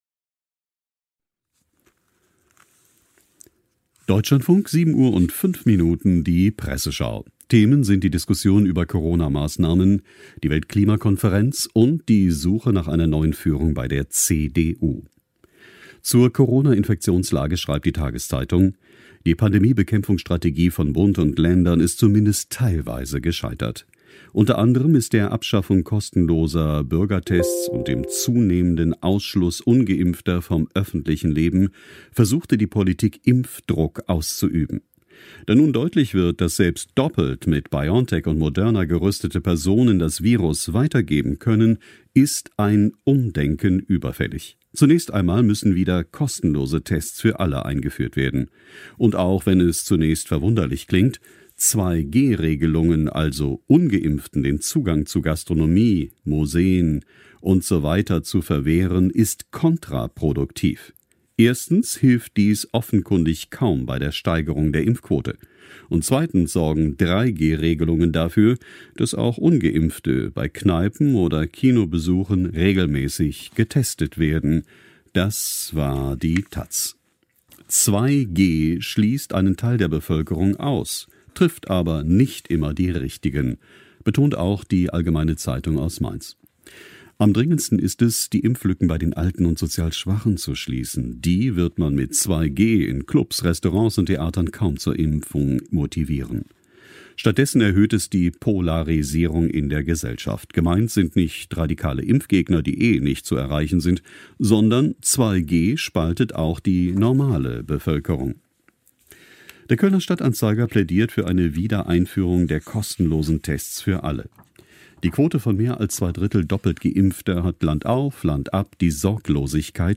Markus Söder, Ministerpräsident Bayern, im Dlf am 8.11.2021: